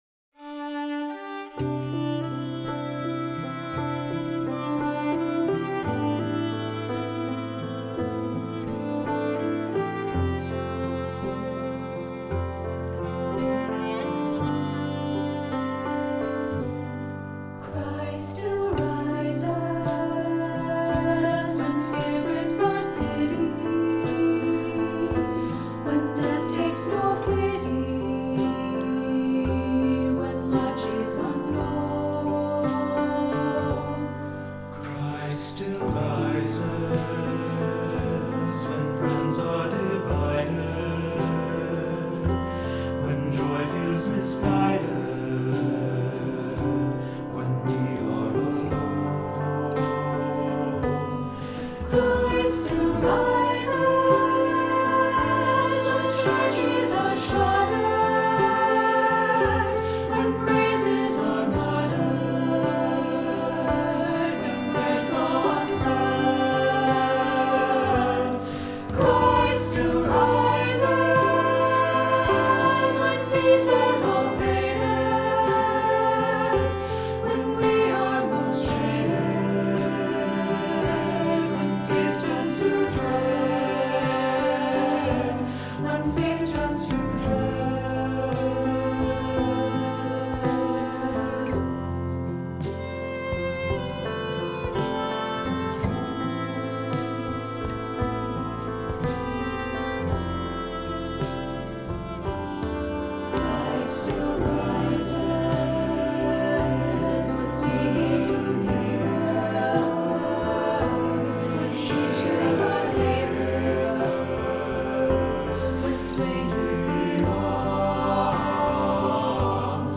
Voicing: SATB and Assembly